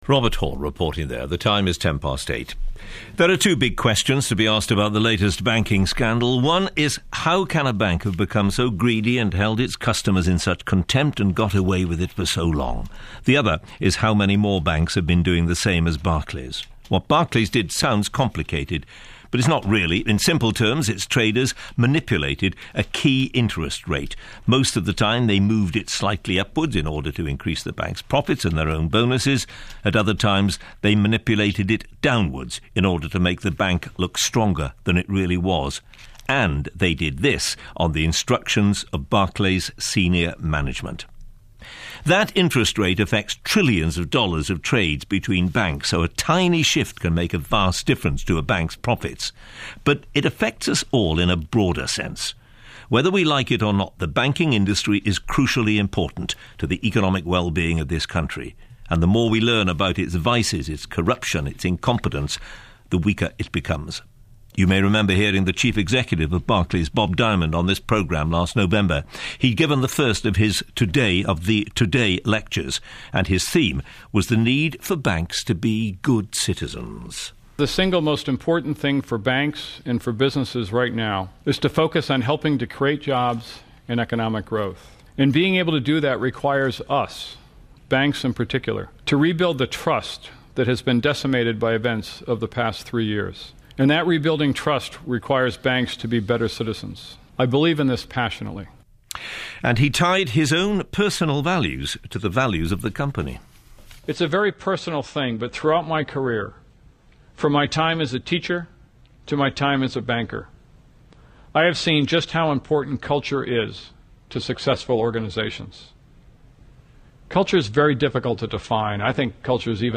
Some of the reporting from this morning’s Today Programme, including a clip from Bob Diamond’s lecture on corporate culture, an interview with a former Barclays CEO, Martin Taylor, and the inevitable Robert Peston comment can be heard here.